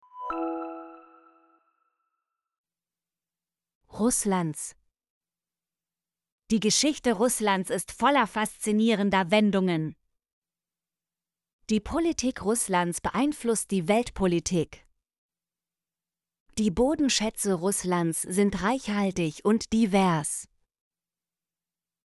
russlands - Example Sentences & Pronunciation, German Frequency List